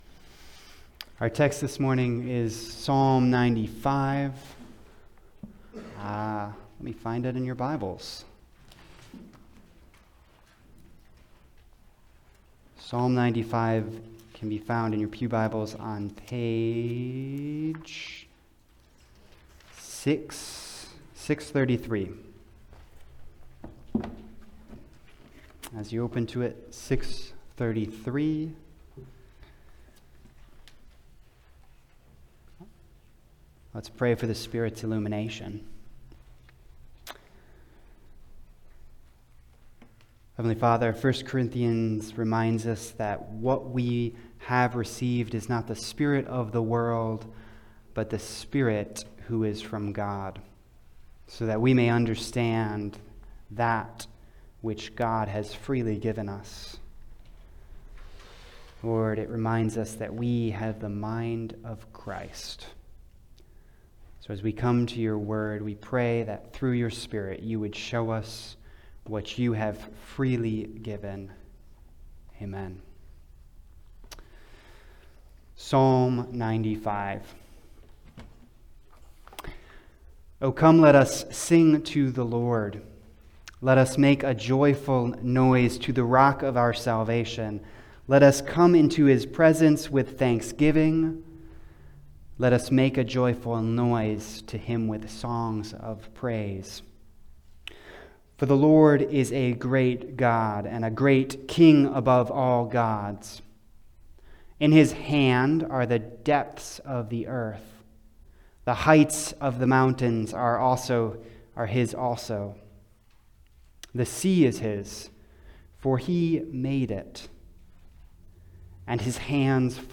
Passage: Psalm 95 Service Type: Sunday Service